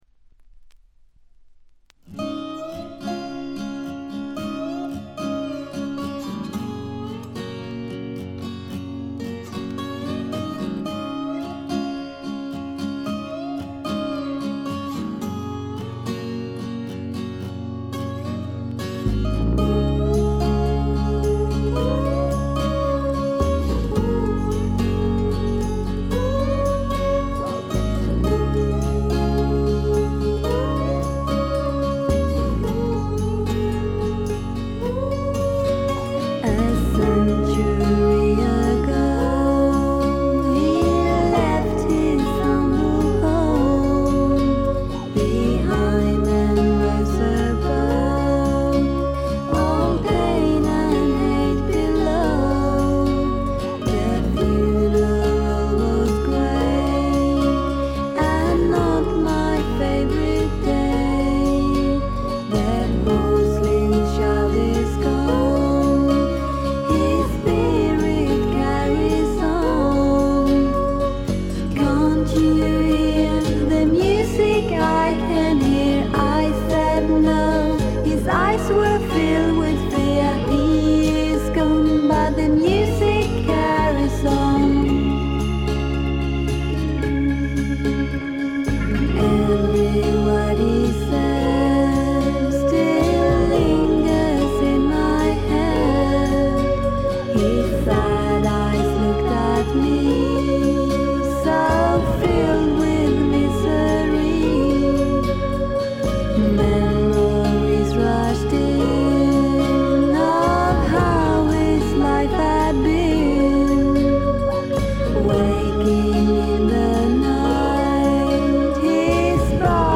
静音部での軽いチリプチ程度。
ちょっと舌足らずなクリスタルな甘えん坊ヴォイスが実に魅力的でノックアウト必至。
試聴曲は現品からの取り込み音源です。